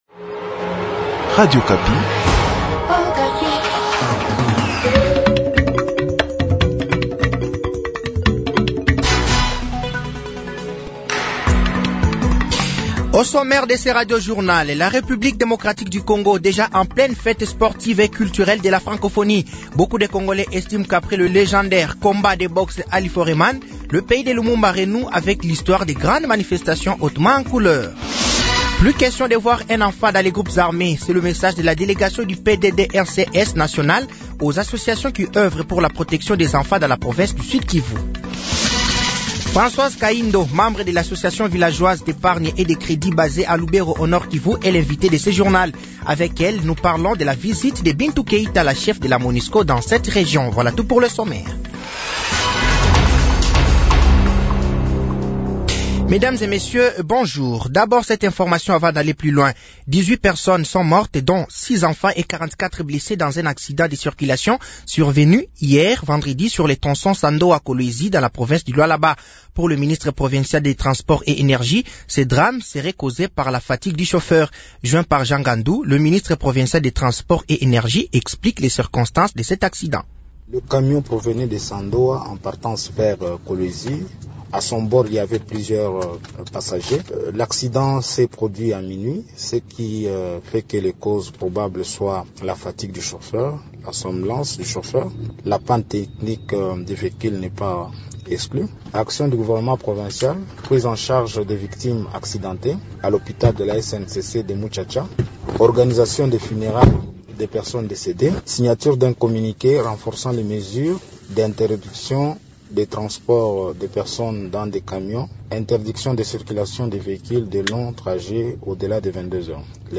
Journal français de 12h de ce samedi 29 juillet 2023